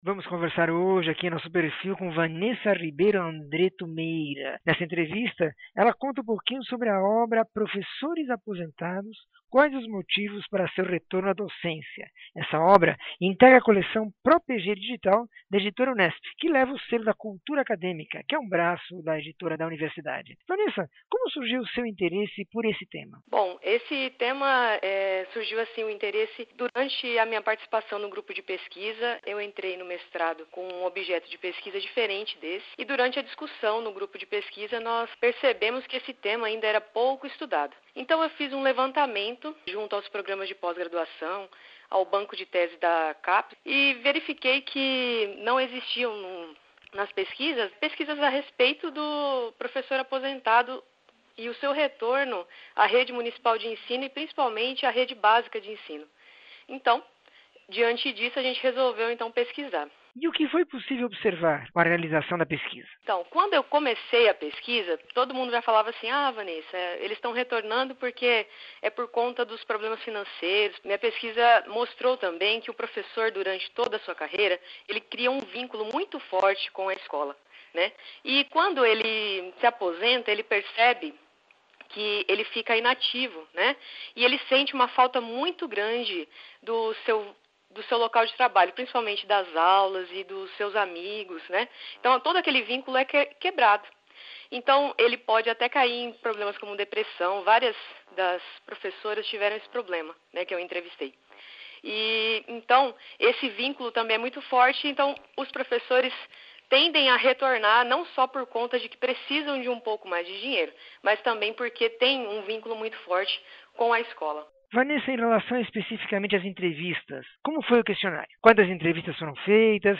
entrevista 2197